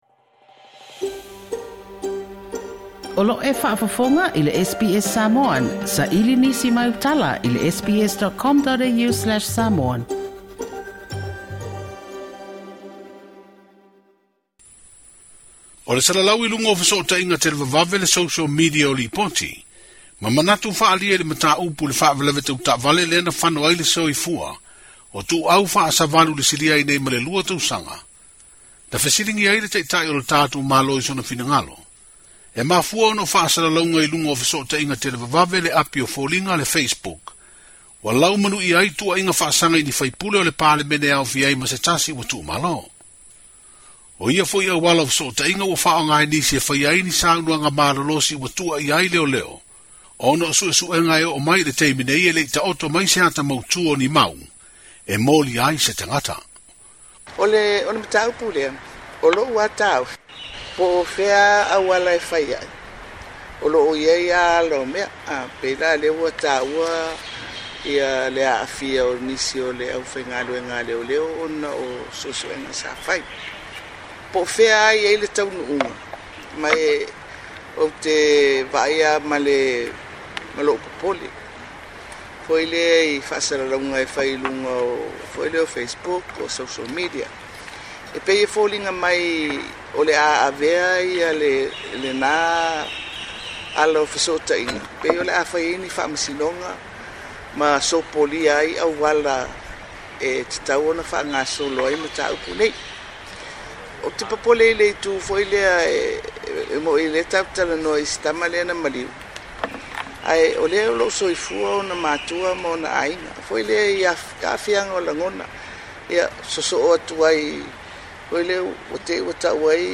O le saunoaga a le palemia o Samoa, Fiame Naomi Mata'afa, na faasino tonu i le tele o faamatalaga o loo faasalalauina i luga o le Facebook i le mata'upu i se taule'ale'a na maliu i le alatele i le lua tausaga ua mavae ae o'o mai i le taimi nei e le'i maua le taavale ma le 'ave taavale na tupu ai le faalavelave. O loo tuua'ia ai e nisi se sui faipule ua maliu ma le vasega o leoleo ma nisi i le faalavelave.